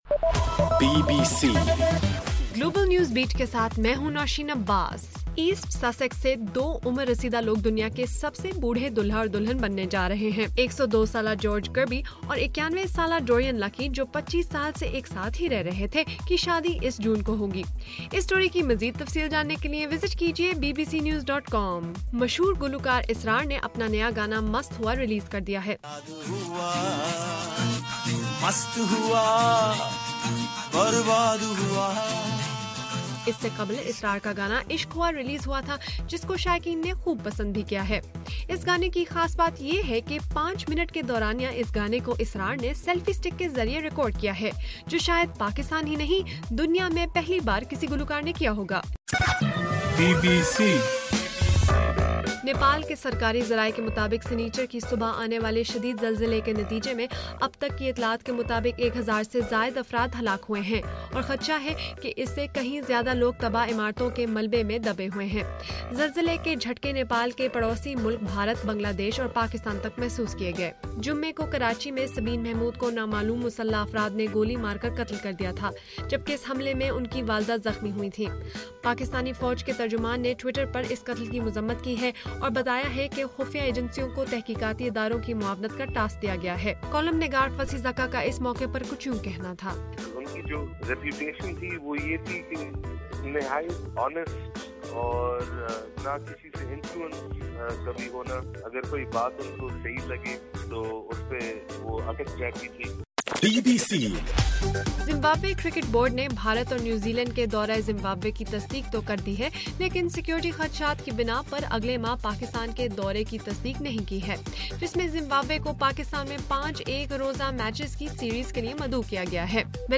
اپریل 26: صبح 1 بجے کا گلوبل نیوز بیٹ بُلیٹن